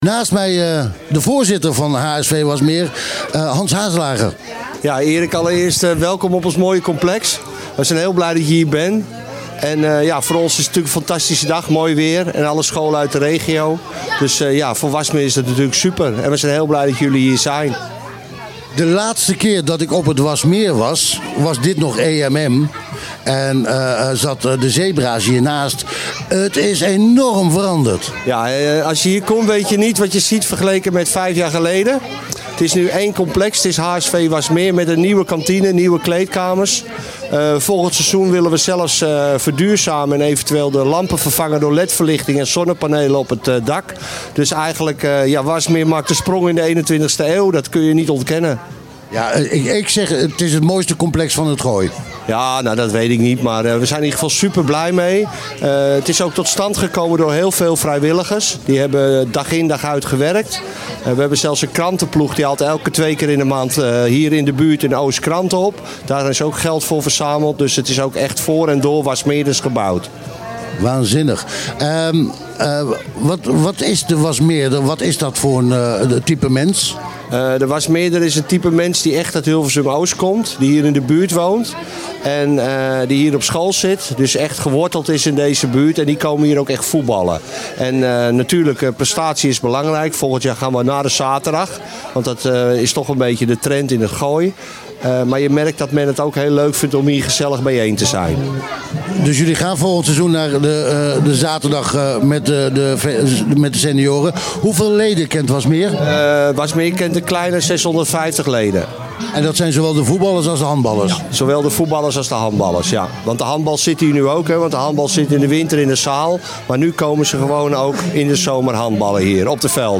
Deze week kwam sportcafe Achter 't Net vanaf het zonnige terras van HSV Wasmeer waar het schoolvoetbaltoernooi plaatsvond.